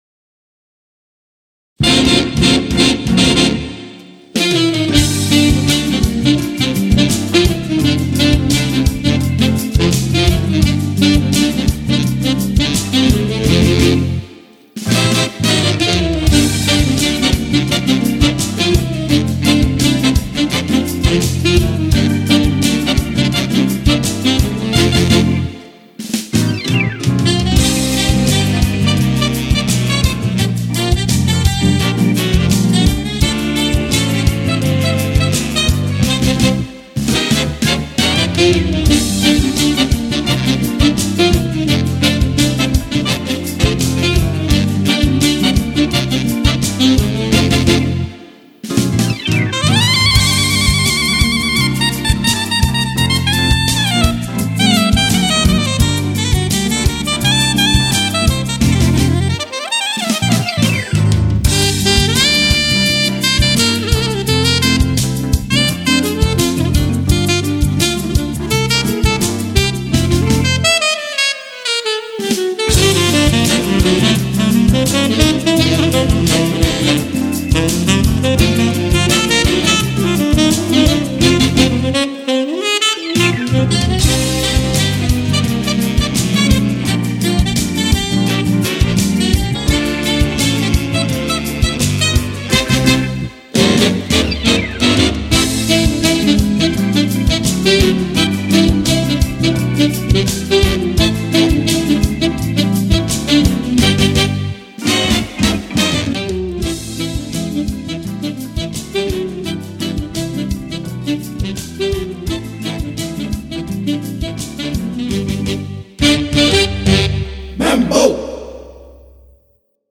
Clarinet, Alto, Tenor, Soprano & Baritone Saxes, Keyboards
Multi-tracking